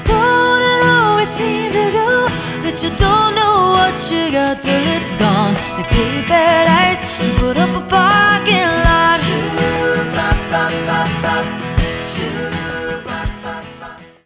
Click the music notes for a sad theme song.